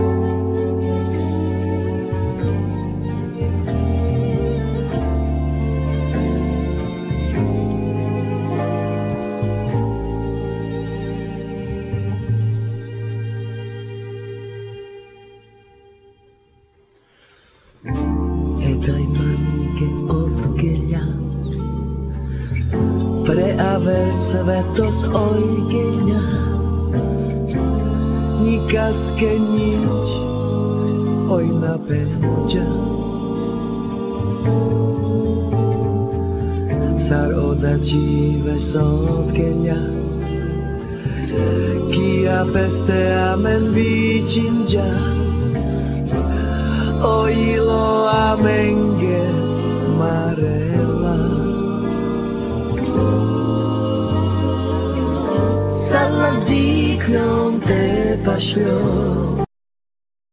Vocal,Cello
Guitars,Vocal
Piano,Fender piano model 88,Vocal
Accordion
Violin
Bass-guitar,Double bass
Drums